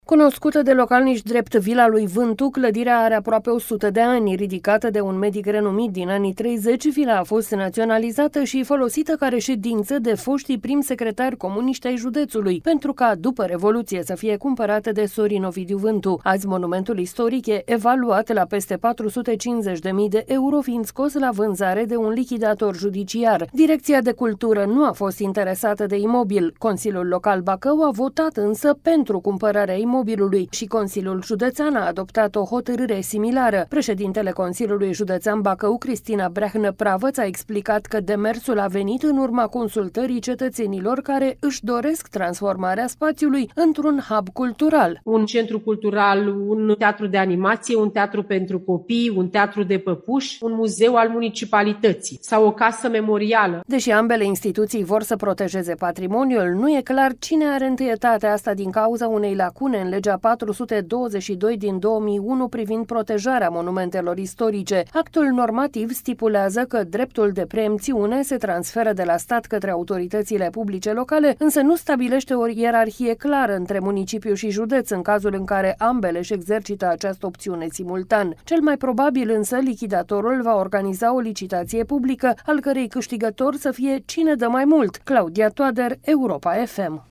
Președintele Consiliului Județean Bacău, Cristina Breahnă-Pravăț, a explicat că demersul a venit în urma consultării cetățenilor, care își doresc transformarea spațiului într-un hub cultural
„Un centru cultural, un teatru de animație, un teatru pentru copii, un teatru de păpuși, un muzeu al municipalității sau o casă memorială”, a spus președintele Consiliului Județean Bacău, Cristina Breahnă-Pravăț.